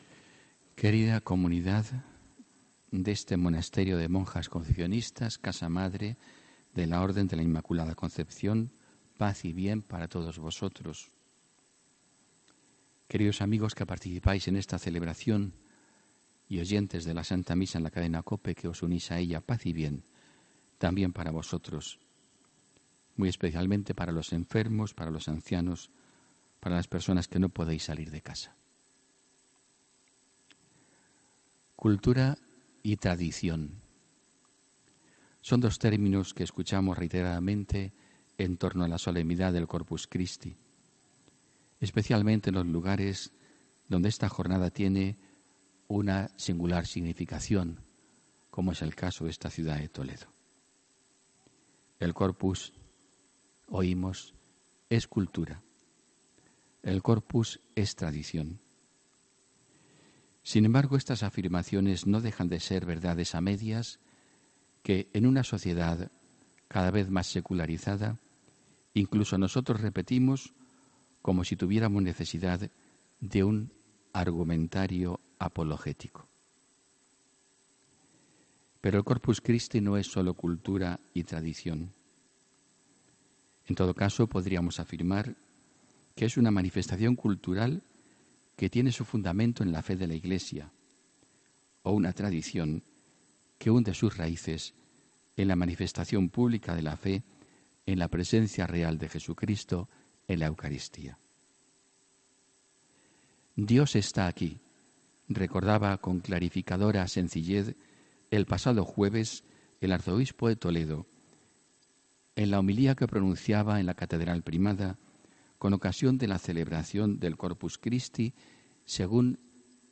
HOMILÍA 3 JUNIO 2018